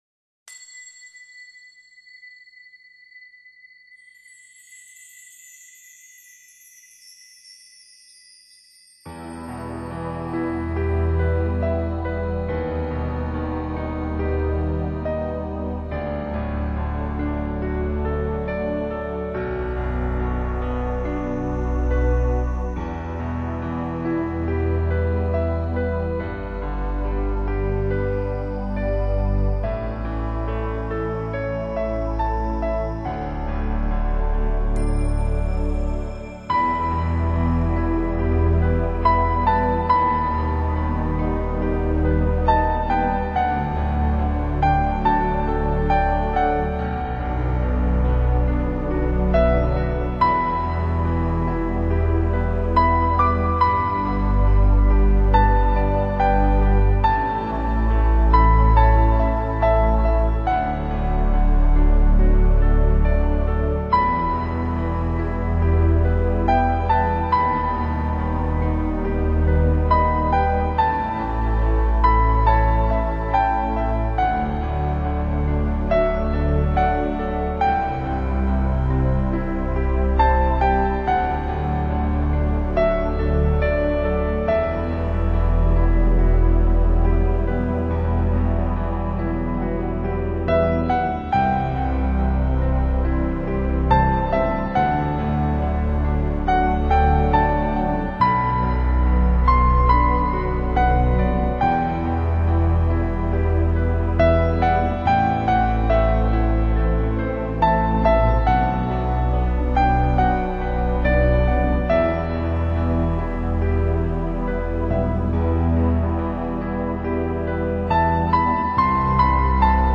耳边是否又荡出那宁静、悠远的灵韵。